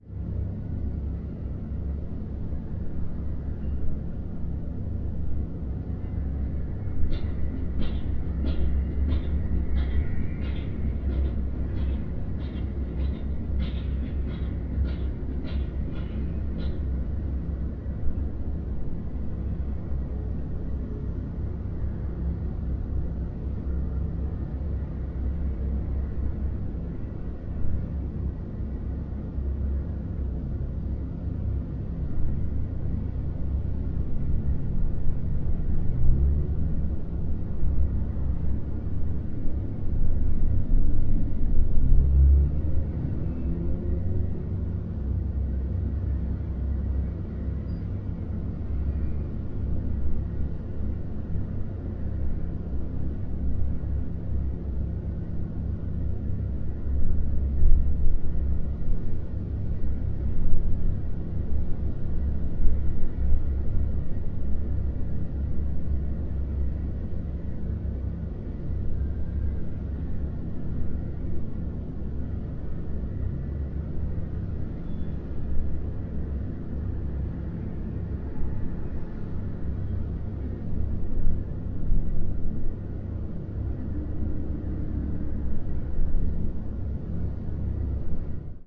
Noisey现场录音 " 城市景观
描述：用DS40创建脉冲响应时录制的声音。
Tag: 氛围 现场记录